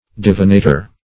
Search Result for " divinator" : The Collaborative International Dictionary of English v.0.48: Divinator \Div"i*na`tor\, n. [L. See Divination .]